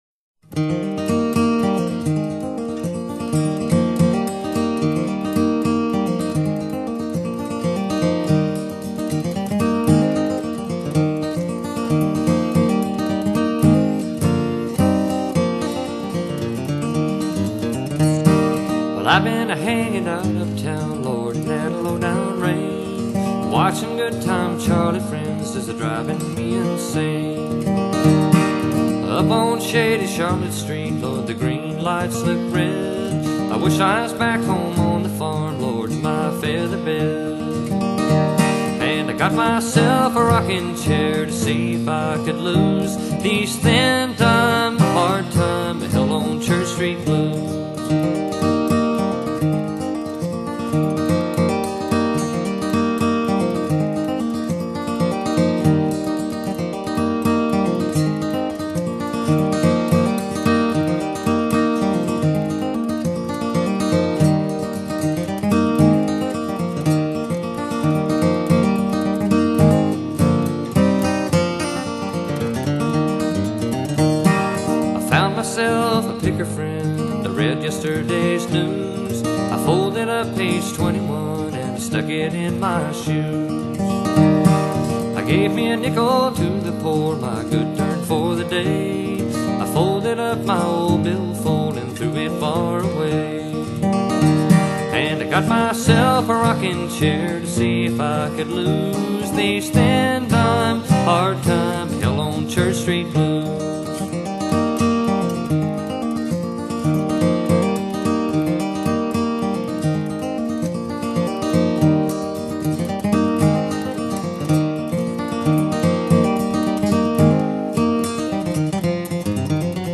【美國鄉村歌曲 1978-1986】